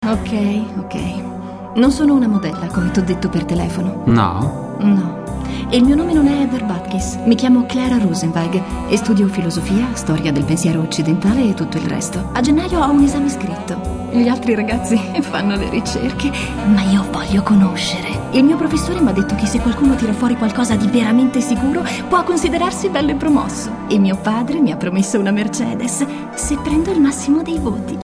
Professional home recording studio. My voice is friendly young and smiling.
Sprechprobe: Sonstiges (Muttersprache):
Italian professional female Voiceover Talent.